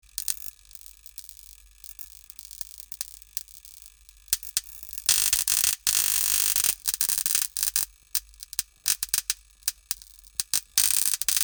Broken-lamp-electrical-buzz-light-humming-5.mp3